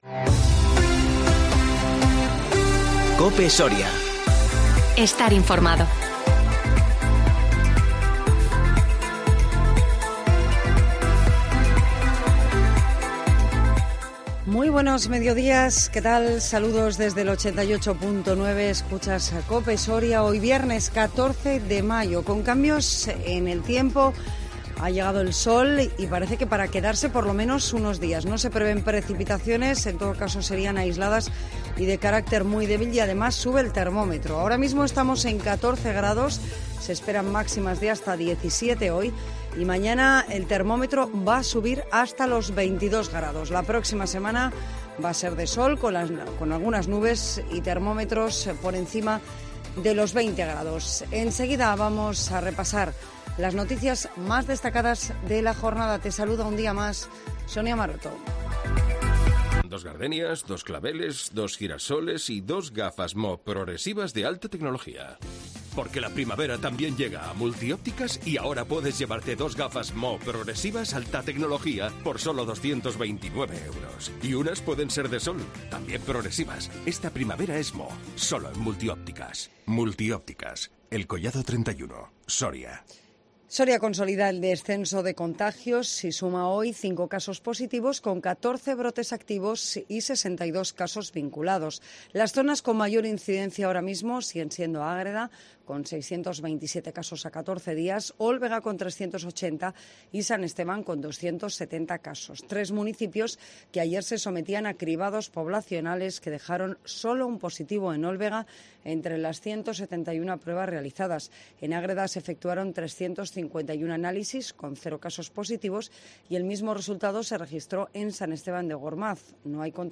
Informativo Mediodía 14 mayo 2021